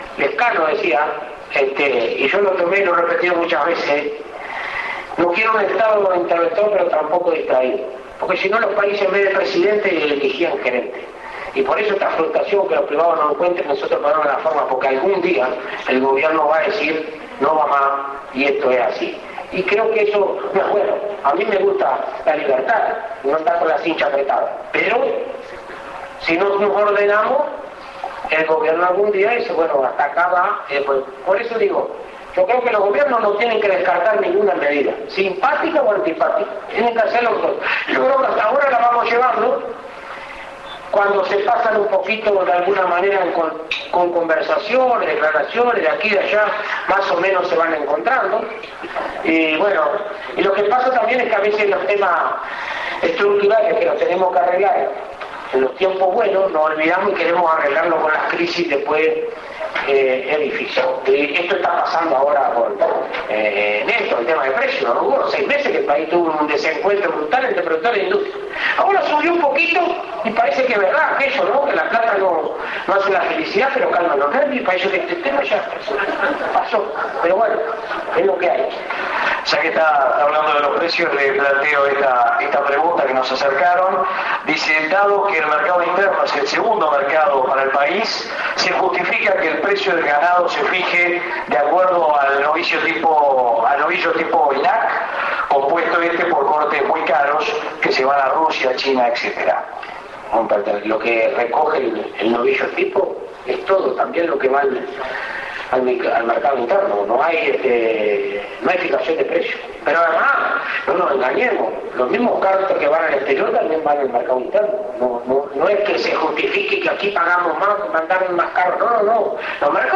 El Presidente del Instituto Alfredo Fratti realizó una evaluación de la gestión, en el marco del Desayuno de Somos Uruguay, en una conferencia titulada .
Ante un diverso auditorio, el titular de INAC presentó a la Institución como un modelo de articulación público- privado, enumerando los logros y los temas que quedarán como agenda pendiente para el corto plazo.
Preguntas de Auditorio. mp3. 7:59